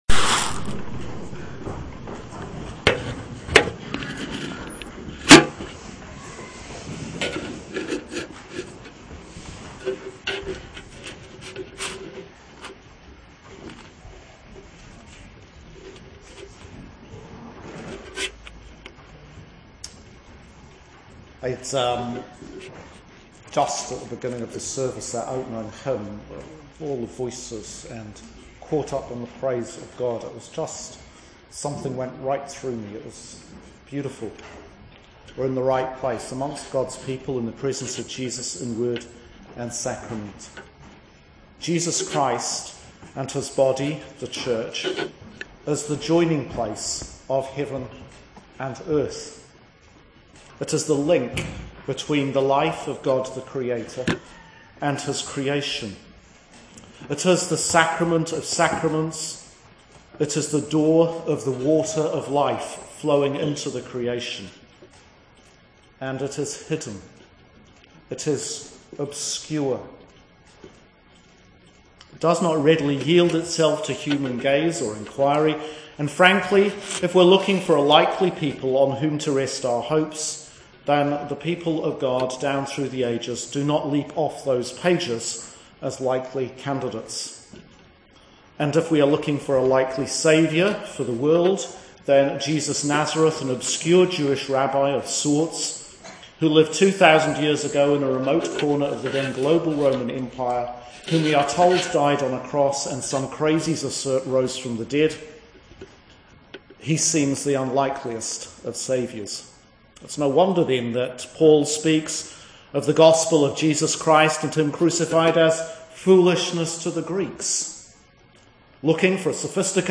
Sermon for the 11th Sunday after Trinity